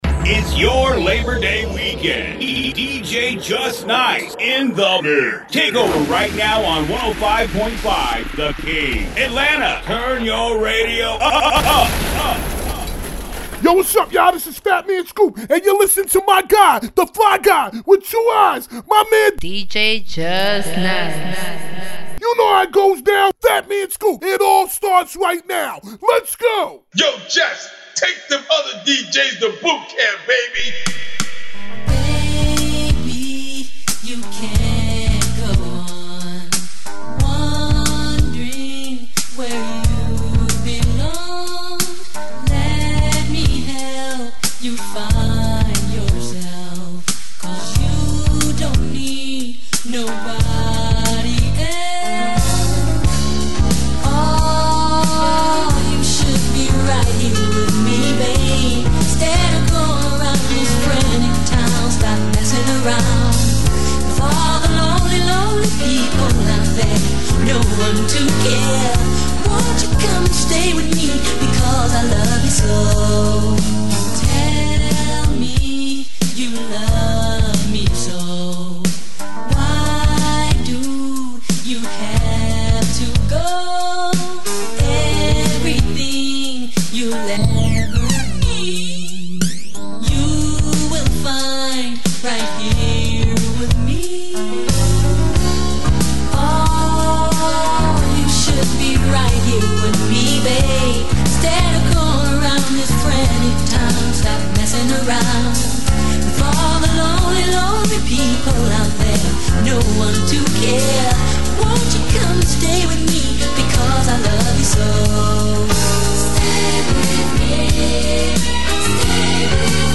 Labor Day Classic Jams